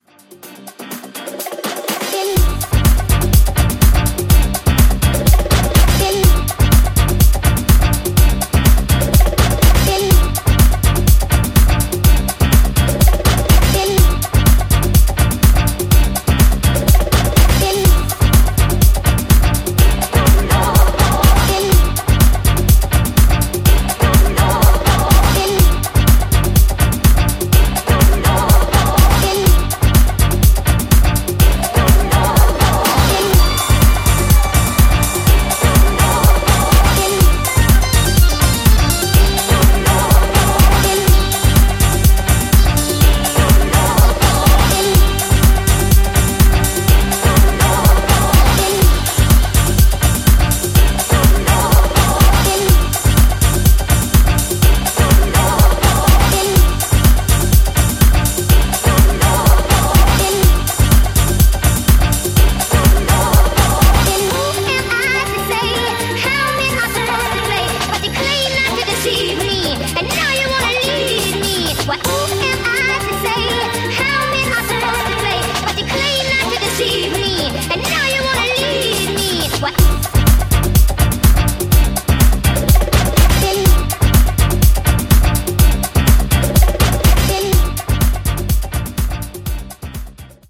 ニューディスコ、ディスコ、ファンク、といったジャンルをファンキーなソウルフルハウスで仕上げたスペシャルなトラック全6曲！
ジャンル(スタイル) NU DISCO / DISCO / HOUSE